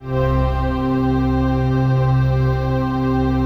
CHRDPAD025-LR.wav